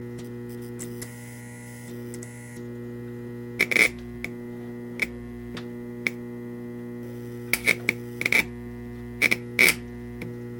Звуки короткого замыкания
На этой странице собраны звуки короткого замыкания — резкие, трещащие и искрящие аудиоэффекты.